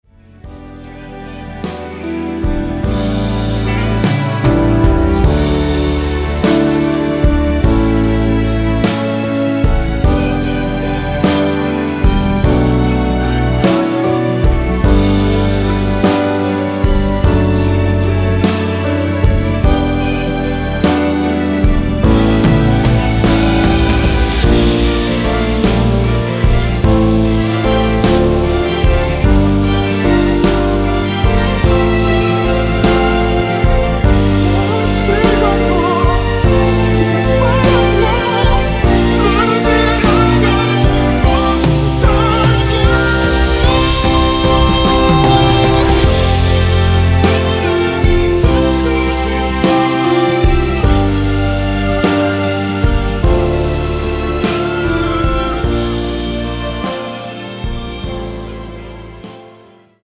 원키에서(-3)내린 코러스 포함된 MR입니다.(미리듣기 확인)
앞부분30초, 뒷부분30초씩 편집해서 올려 드리고 있습니다.
중간에 음이 끈어지고 다시 나오는 이유는